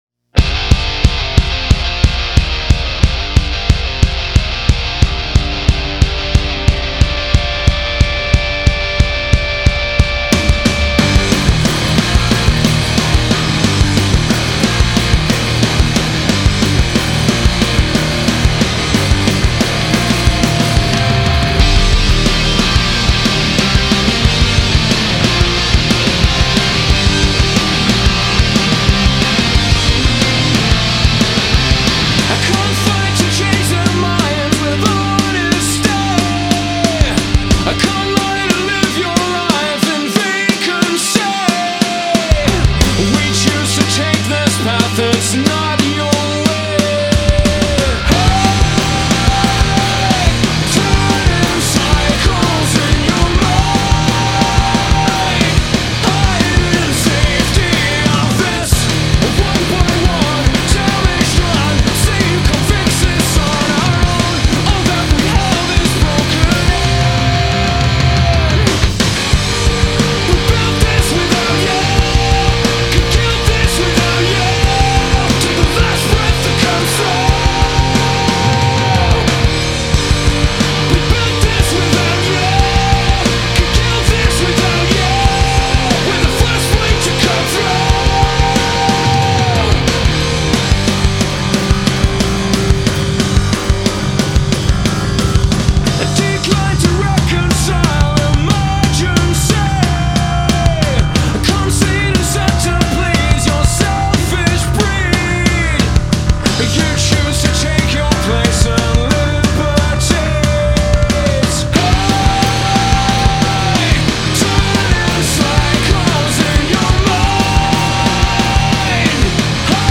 Official website of UK Rock band.